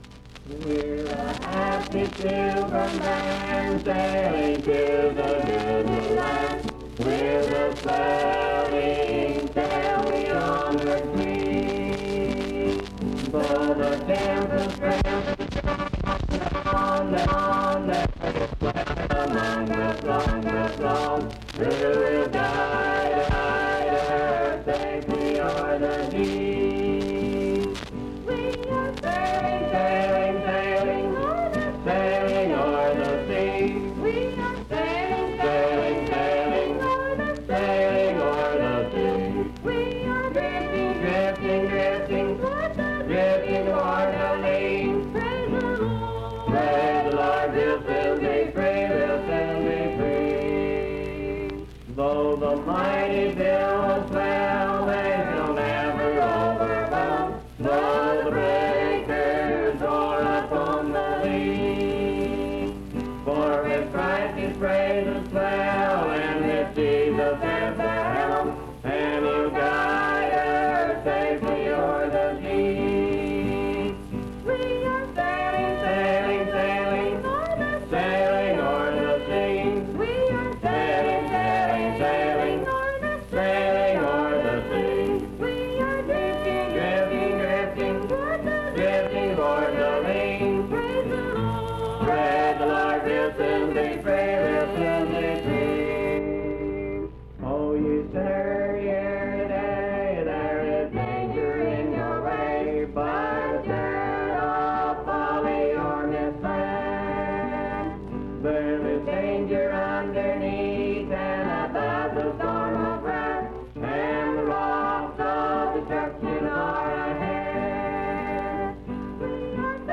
Vocal and guitar performance
Hymns and Spiritual Music
Voice (sung), Guitar
Roane County (W. Va.), Spencer (W. Va.)